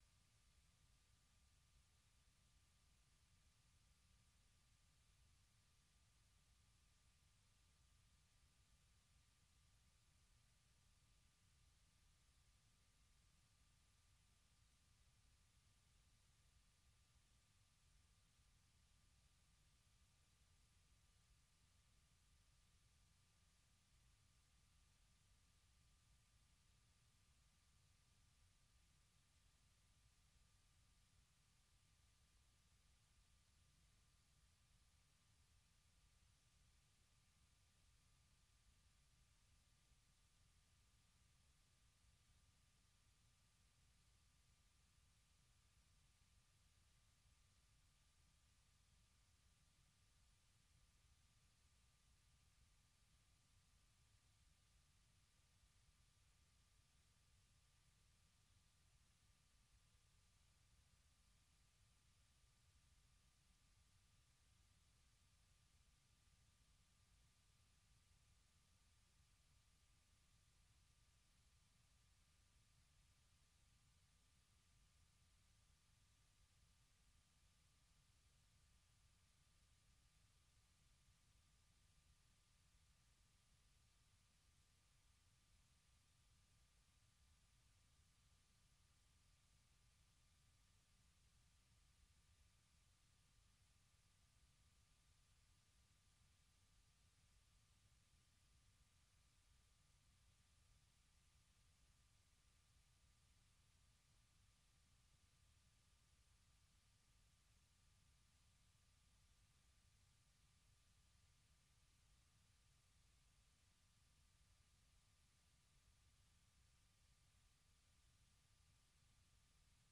Allocution du président Donald Trump devant la session conjointe du Congrès
Vous suivez sur VOA Afrique l'édition spéciale sur l’allocution du président américain Donald Trump devant une session conjointe du Congrès, suivi de la réponse du Parti démocrate.